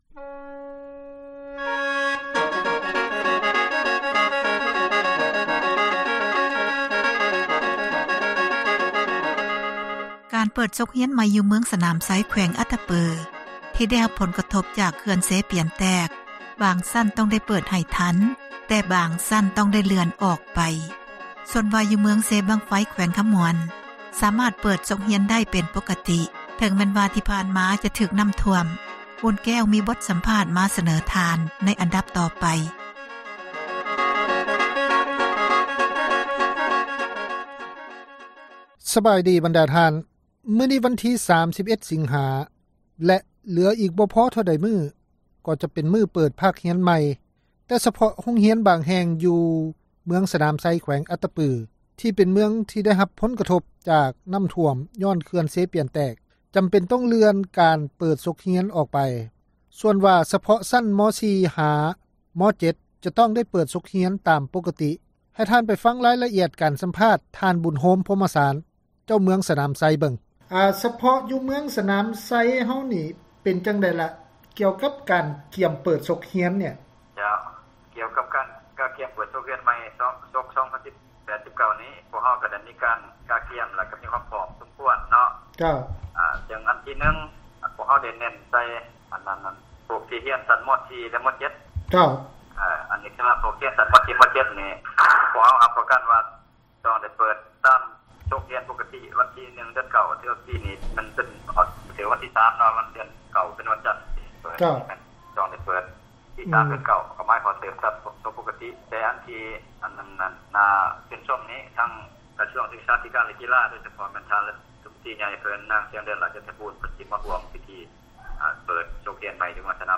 ມີບົດສຳພາດ ມາສະເໜີ.